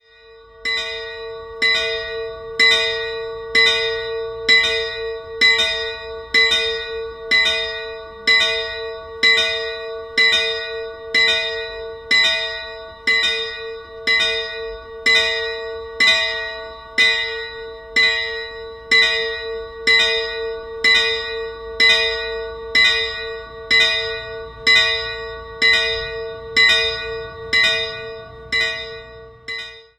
Sie wurde im 14. Jahrhundert errichtet und überrascht im Inneren mit sehr gut erhaltenen spätgotischen Fresken im Chorraum. Die Altäre wurden in der Barockzeit im Gotteshaus aufgestellt. 2-stimmiges Geläut: b''-d''' Die beiden Glocken wurden in der Barockzeit gegossen.